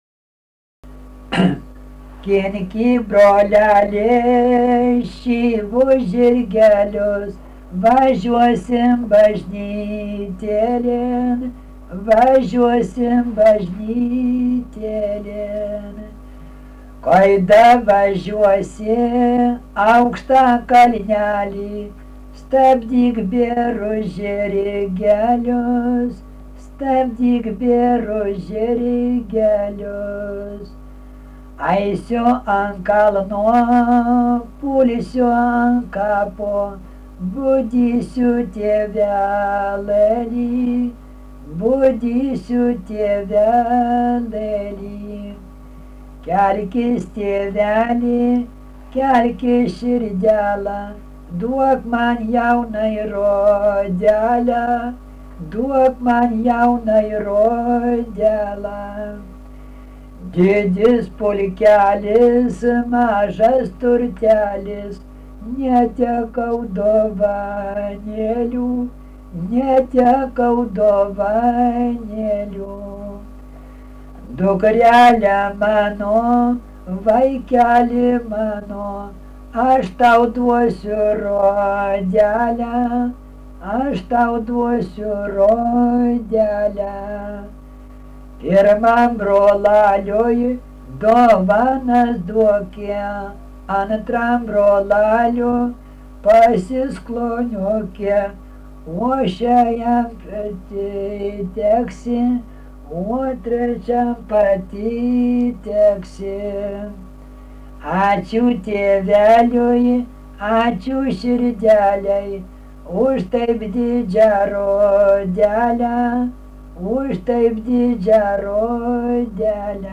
daina
Mikalavas (Alovė)
vokalinis